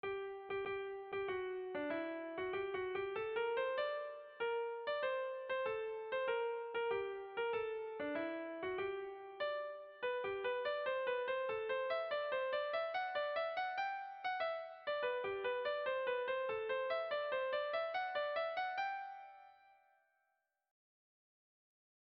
Dantzakoa
Bertsoa 8ko txikia izan arren, doinuaren bi puntuak errepikatzen direnez, 4ko txikien sailean jarri dugu doinua; gero Tralala larala luzagarriz horniturik dator.
Lauko txikia (hg) / Bi puntuko txikia (ip)
AB